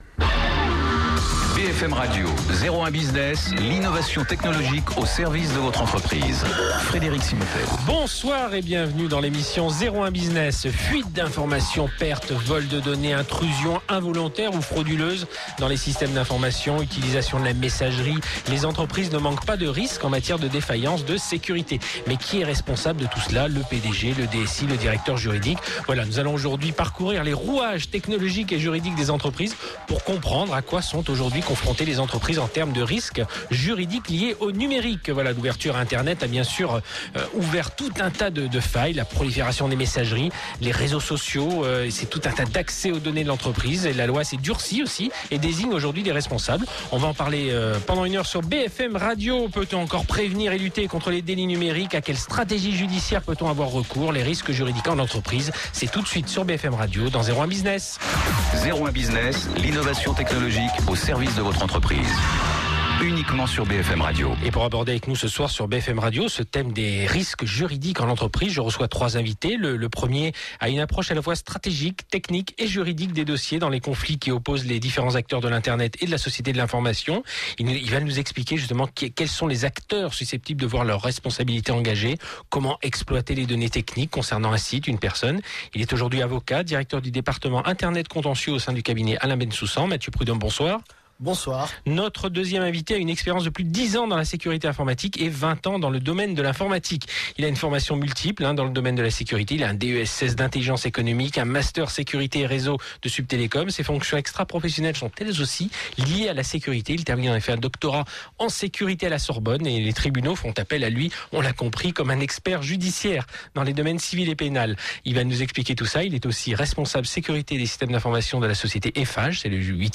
Interview BFM Radio 2010